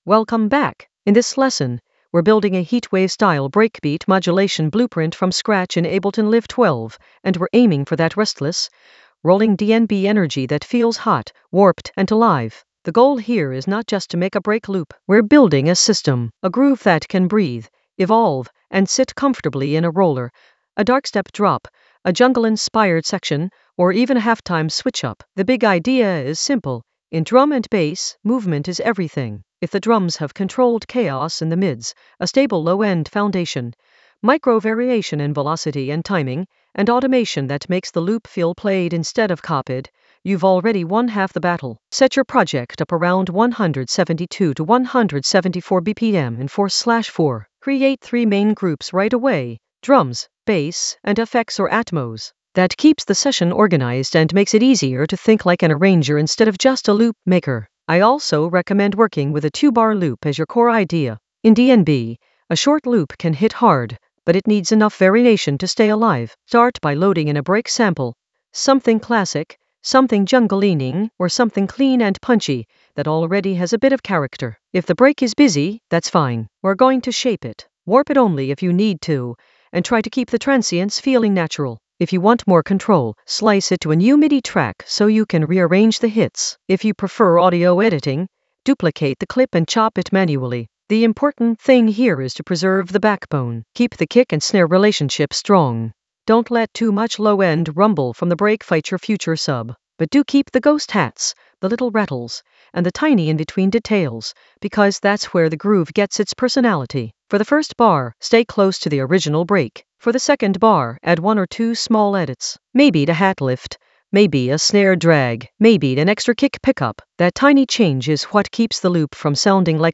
An AI-generated intermediate Ableton lesson focused on Heatwave breakbeat modulate blueprint from scratch in Ableton Live 12 in the Groove area of drum and bass production.
Narrated lesson audio
The voice track includes the tutorial plus extra teacher commentary.